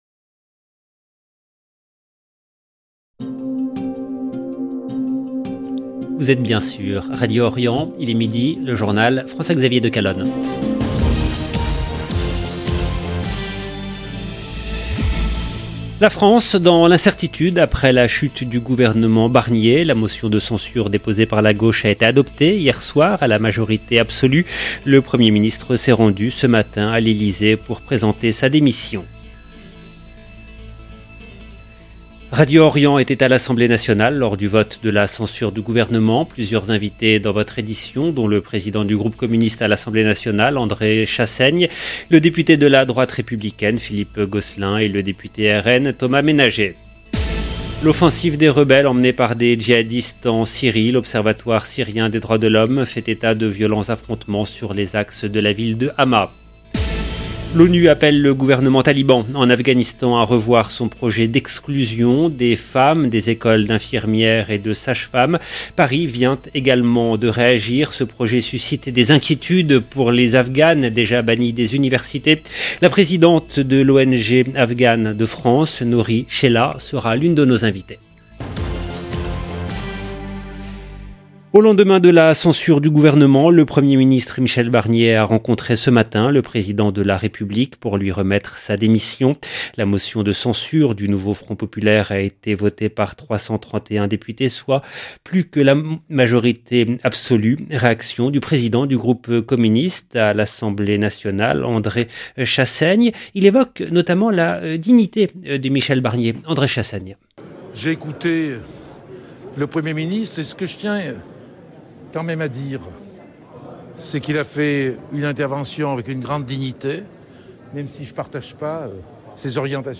Radio Orient était à l’Assemblée Nationale lors du vote de la censure du gouvernement.